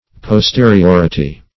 Posteriority \Pos*te`ri*or"i*ty\, n. [Cf. F.